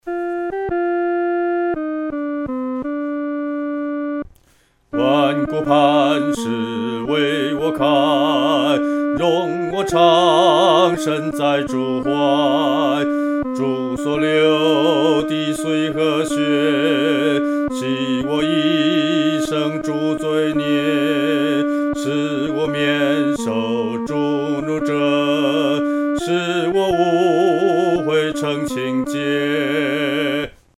独唱（第二一声）
万古磐石-独唱（第二声）.mp3